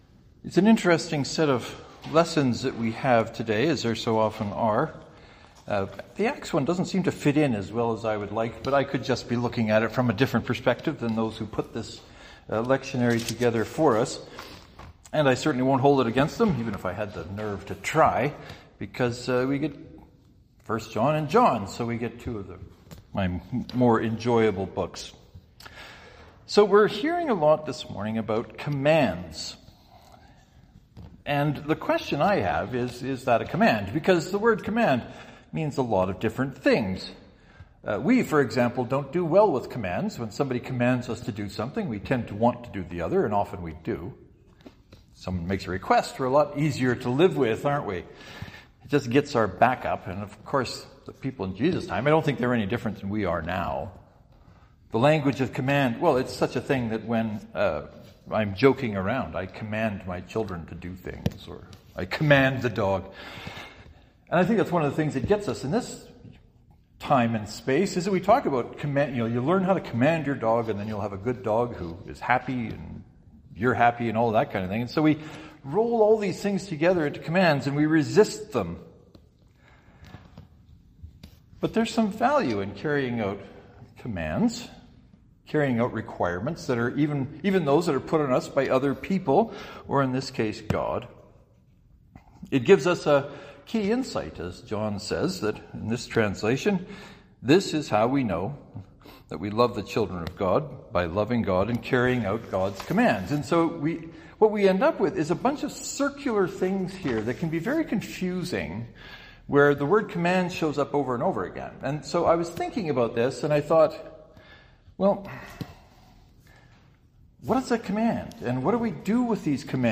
Let me know if I overstep with this sermon.